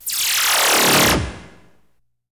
OOZILASER.wav